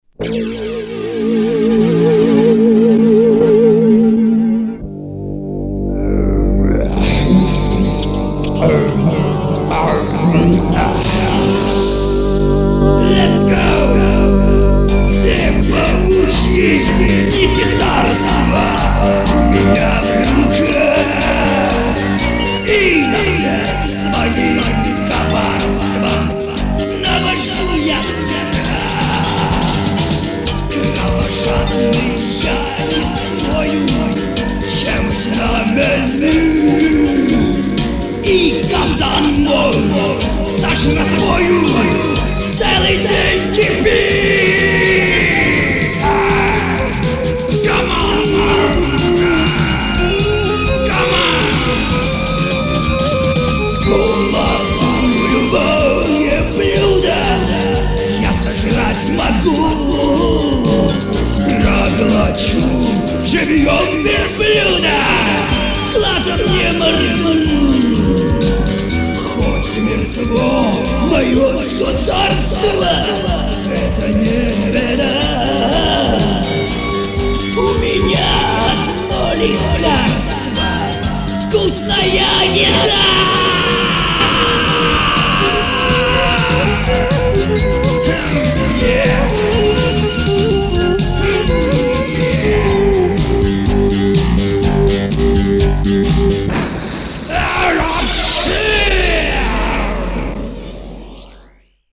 Демо озвучивания